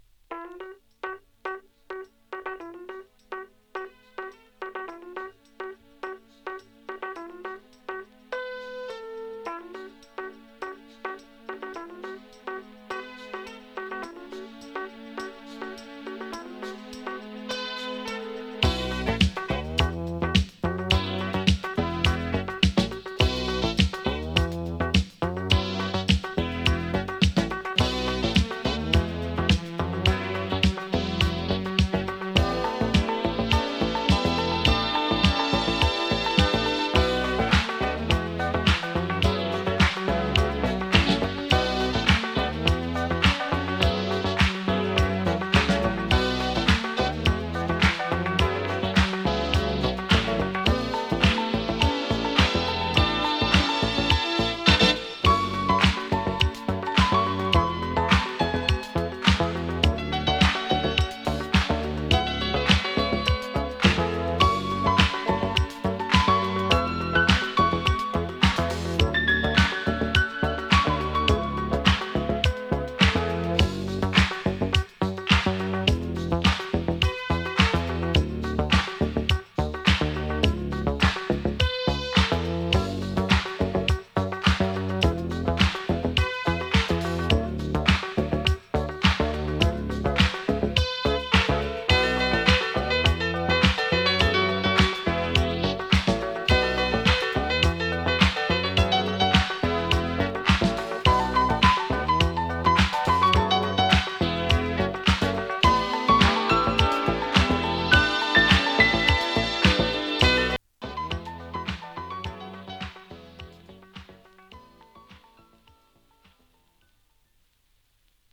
ディスコ
盤 ジャケ M- VG 1982 UK R&B 12
♪Instrumental (5.18)♪